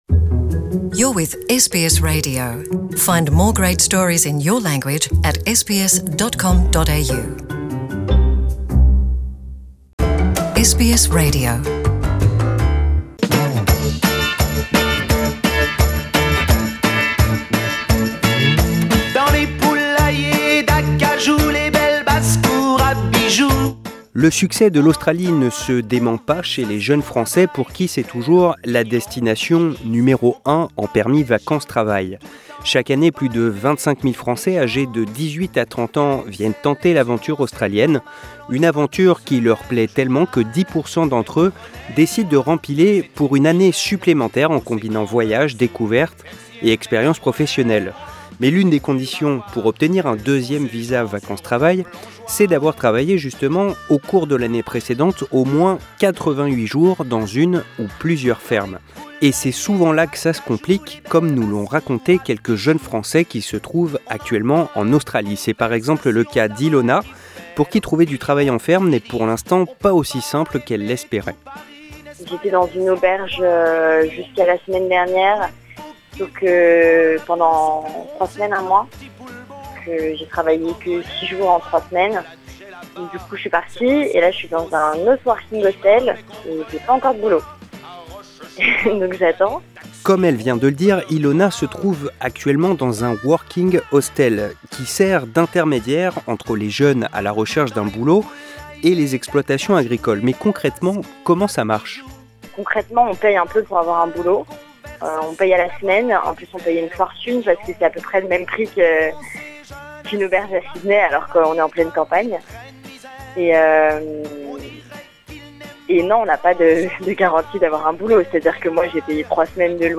Quatre jeunes Français nous racontent leur expérience du travail dans des fermes australiennes, condition indispensable pour obtenir un deuxième visa vacances-travail.